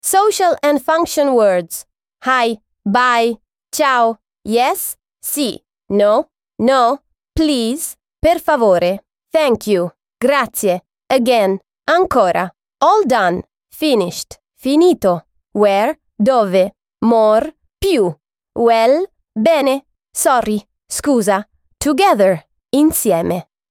Lesson 8